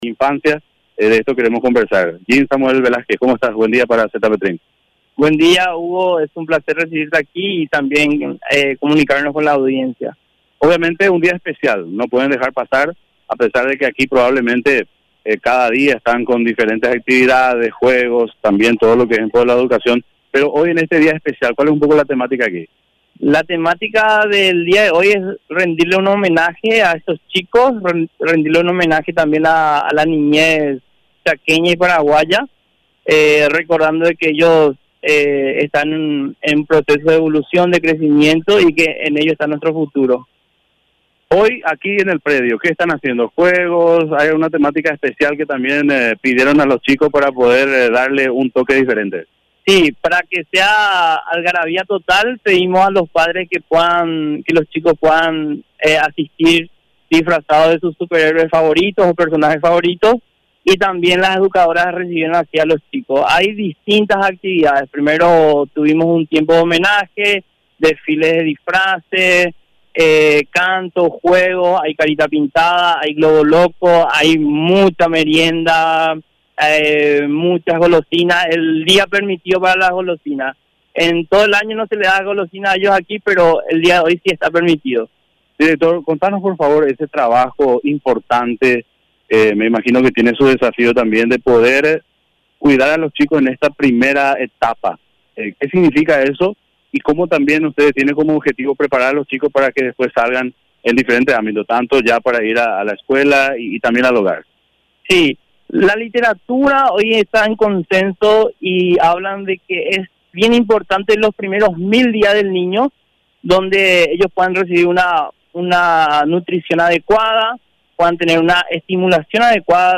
Entrevistas / Matinal 610
Estudio Central, Filadelfia, Dep. Boquerón